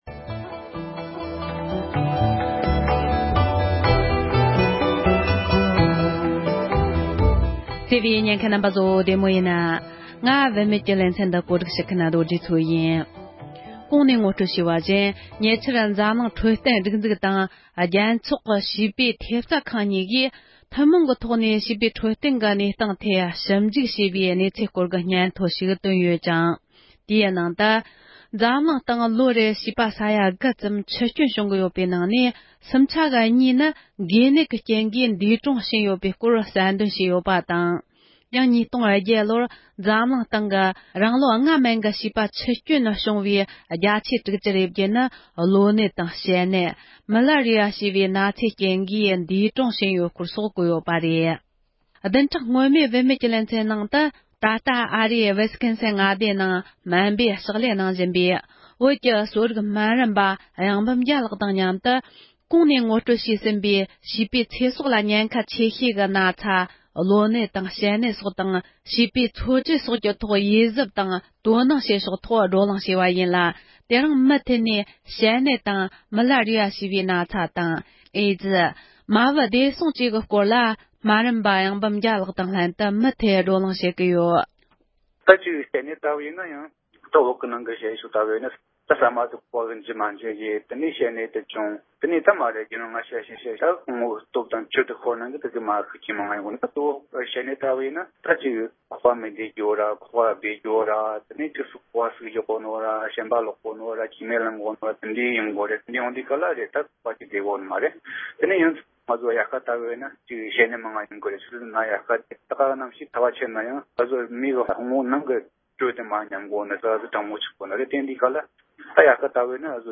བགྲོ་གླེང་ཞུས་པའི་དུམ་བུ་གཉིས་པར་གསན་རོགས་གནོངས༎